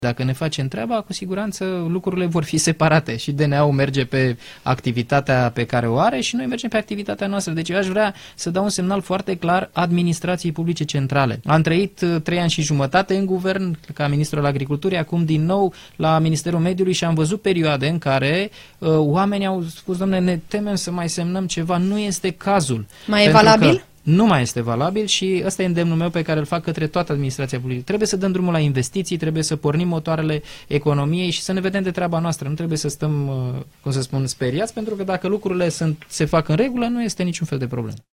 Invitat la Interviurile Europa FM, viceprim-ministrul din partea ALDE a declarat că în Administrație, a trecut vremea când angajaților le era teamă să semneze acte de frica procurorilor anticorupție.